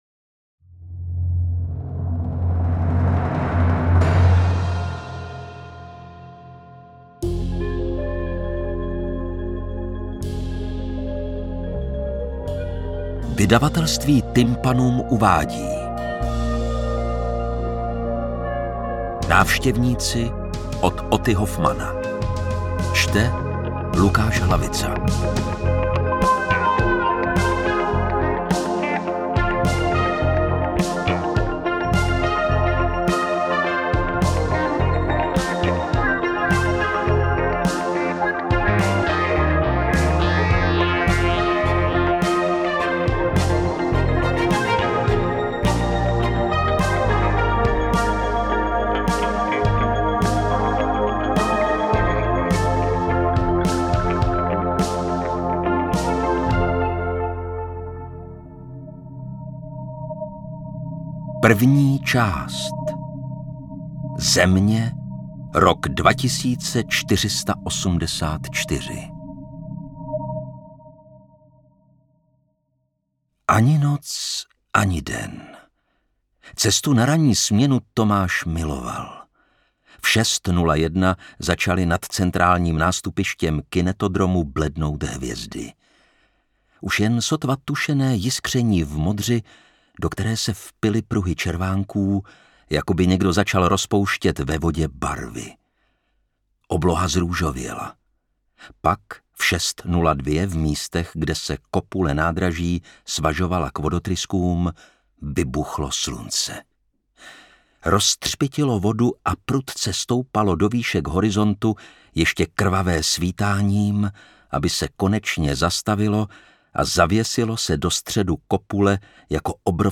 Interpret:  Lukáš Hlavica
AudioKniha ke stažení, 53 x mp3, délka 6 hod. 56 min., velikost 594,7 MB, česky